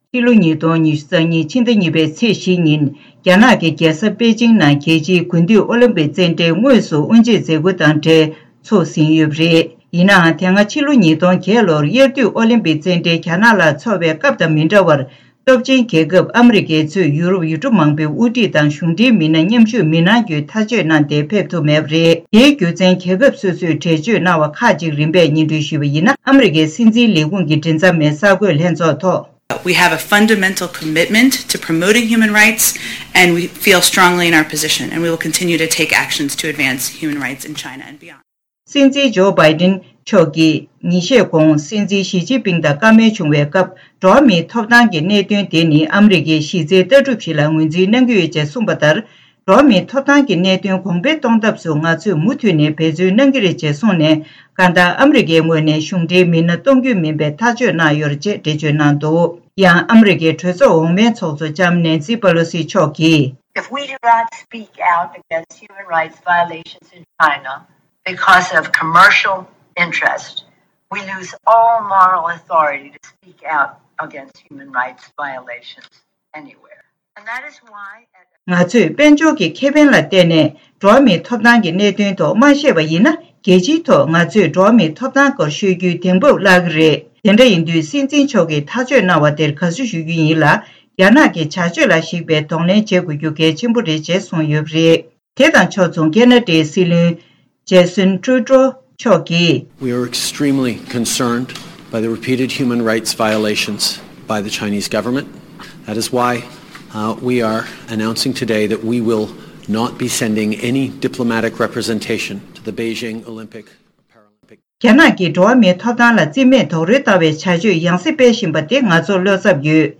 གསར་འགྱུར་དང་འབྲེལ་བའི་ལེ་ཚན་ནང་།